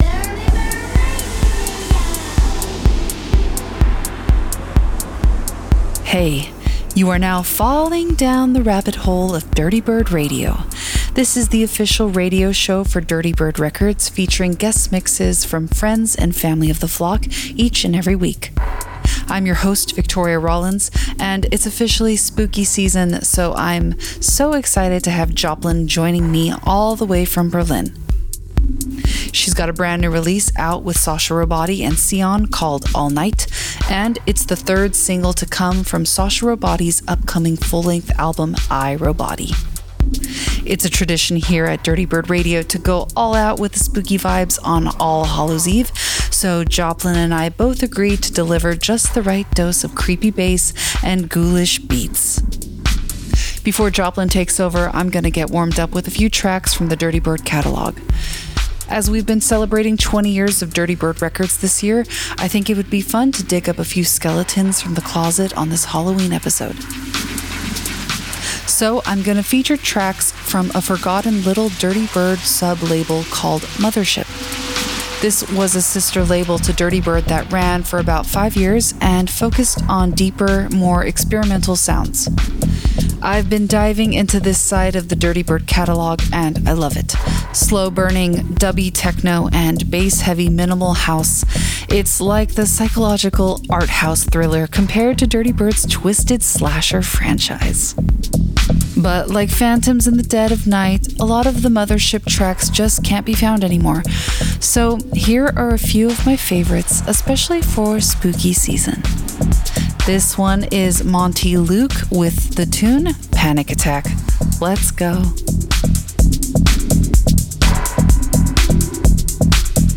continues the haunting vibes with a set